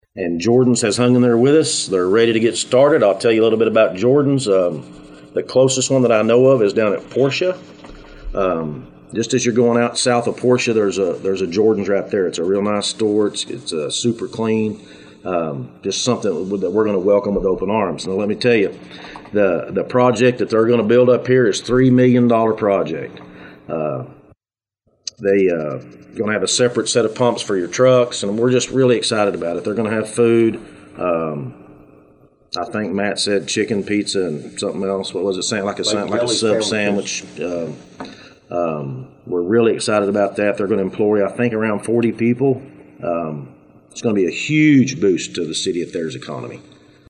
Today the City of Thayer held a press conference to talk about their plans for the project.